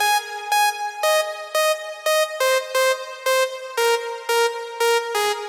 合成器循环13
描述：一个嗡嗡声的合成器循环。
Tag: 175 bpm EDM Loops Synth Loops 945.14 KB wav Key : Unknown FL Studio